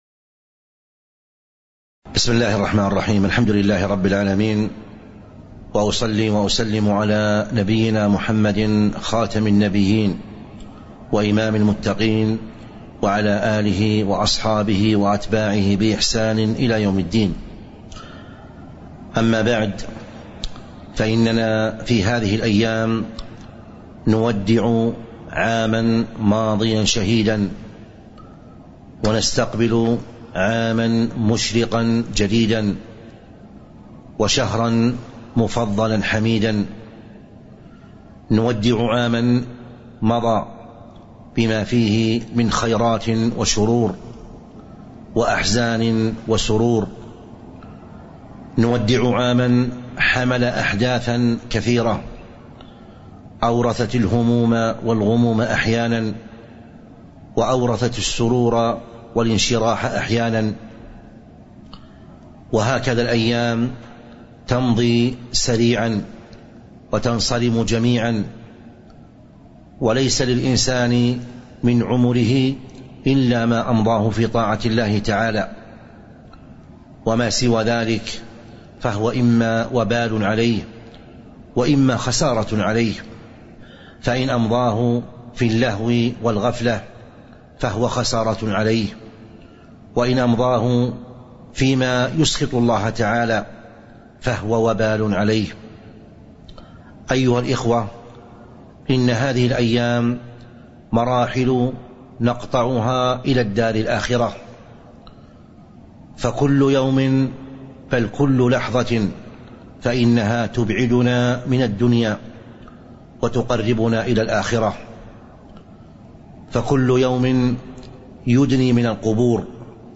تاريخ النشر ١ محرم ١٤٤٤ المكان: المسجد النبوي الشيخ